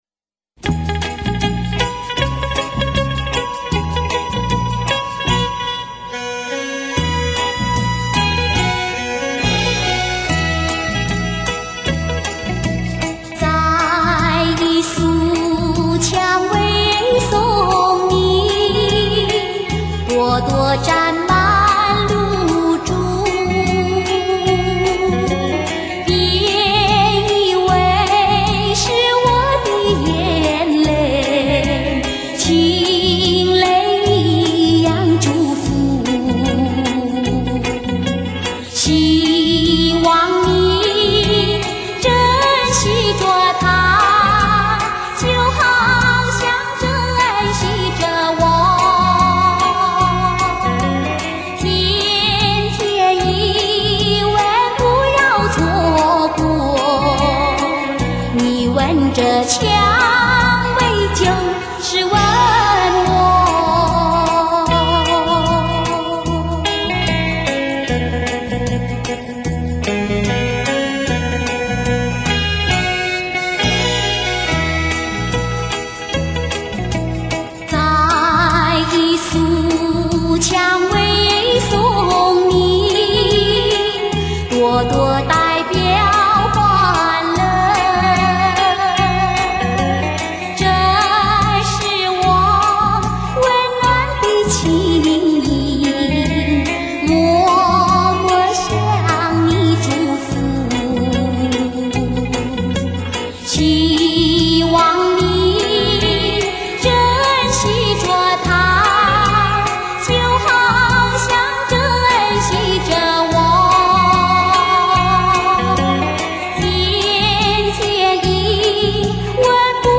自购碟原抓，网络首发
大陆早期发行，音质一般，不喜勿下。